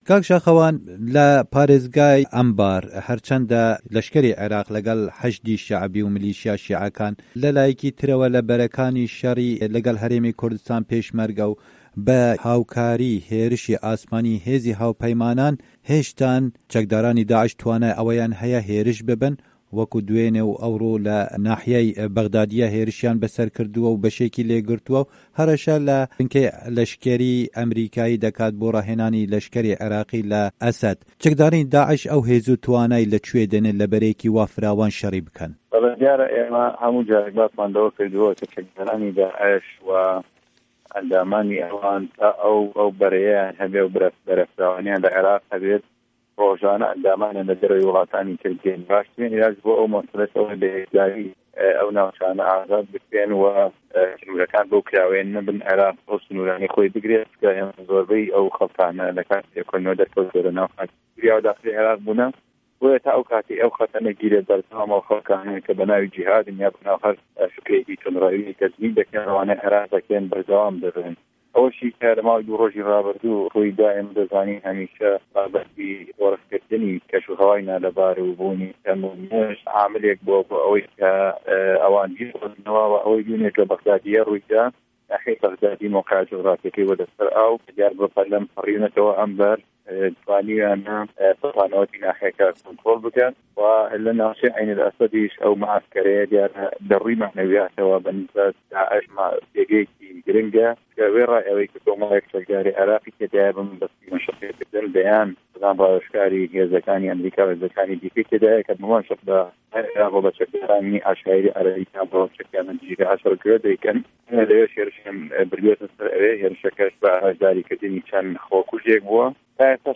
Endamê komîteyaa parastin û berevaniya parlamena Îraqî Şaxewan Delo di hevpeyvînekê de ligel Dengê Amerîka dibêje, lawaziya leşkerê
Hevpeyvin digel Şaxewan Delo